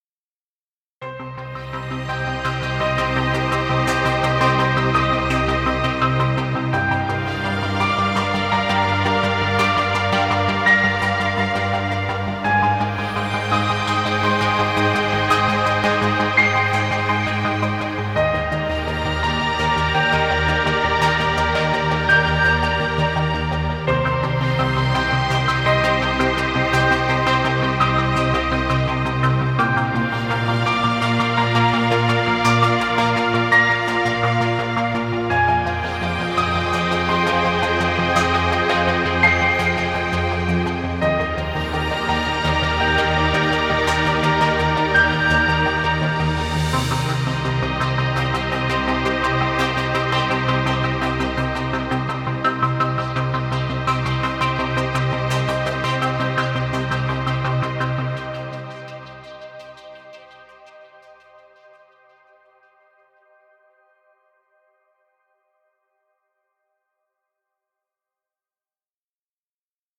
Ambient music. Background music Royalty Free.